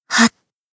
BA_V_Misaki_Battle_Shout_3.ogg